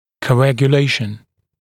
[kəuˌægjə’leɪʃn][коуˌэгйэ’лэйшн]коагуляция, коагулирование, свертывание